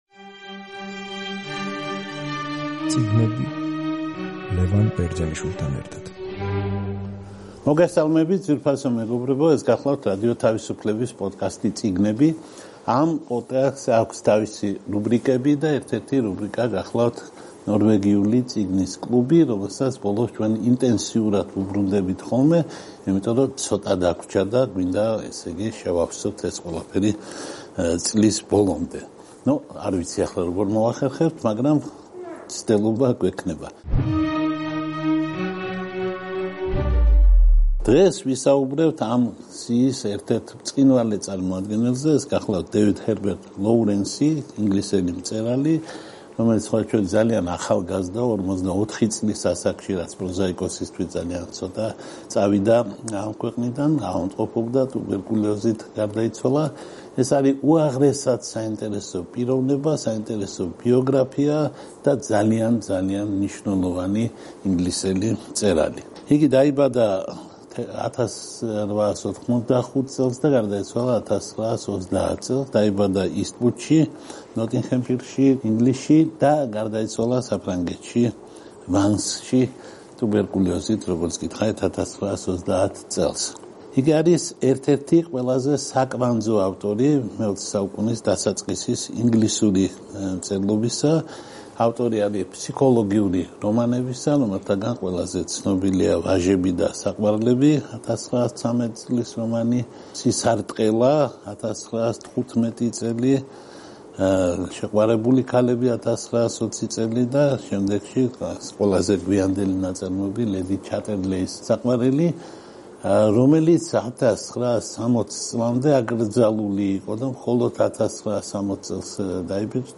რადიო თავისუფლების პოდკასტი „წიგნები“ და მისი რუბრიკა „ნორვეგიული წიგნის კლუბი“ წარმოგიდგენთ საუბარს დიდ ინგლისელ მწერალ დევიდ ჰერბერტ ლოურენსზე და მის შედევრზე „ვაჟები და საყვარლები”.